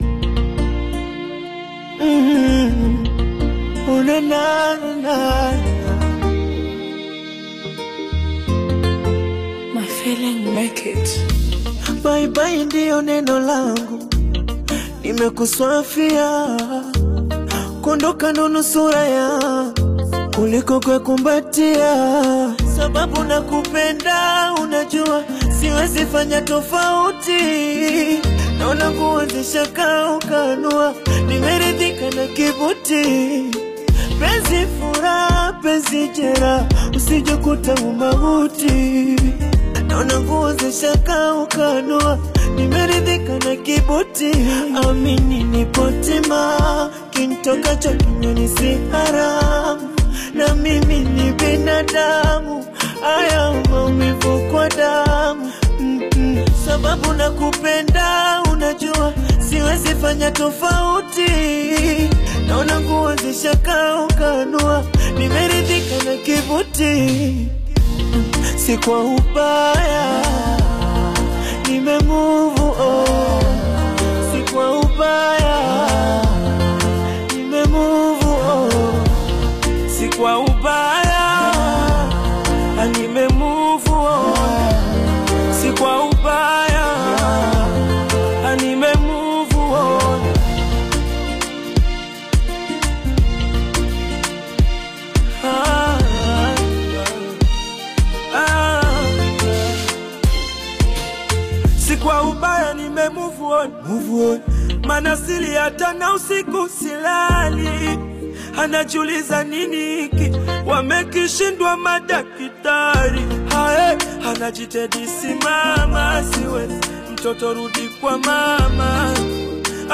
African Music You may also like